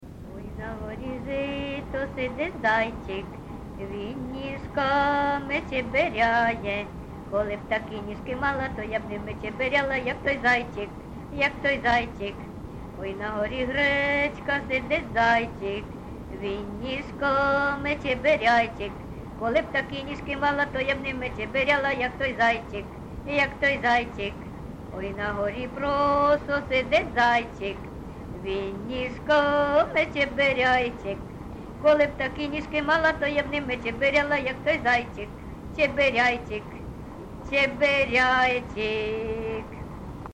ЖанрІгри
Місце записус. Харківці, Миргородський (Лохвицький) район, Полтавська обл., Україна, Полтавщина